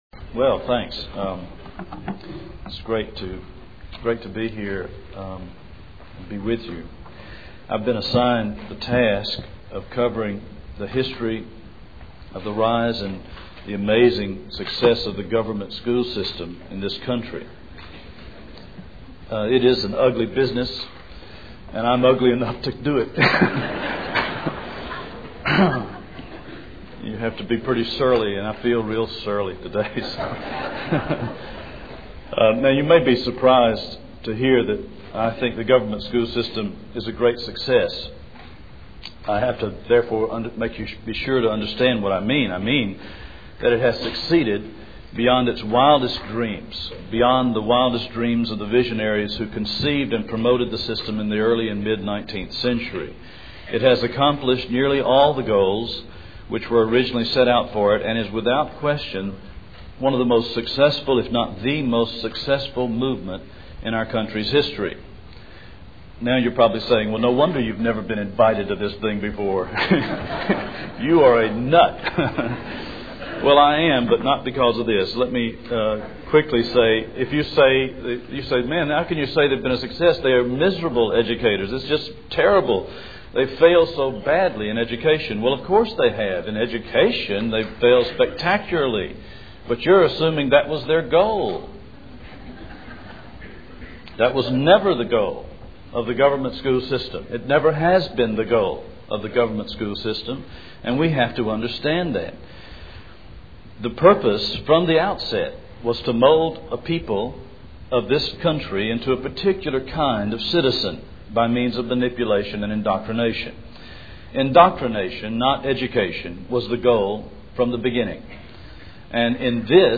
2000 Workshop Talk | 0:46:33 | All Grade Levels, Culture & Faith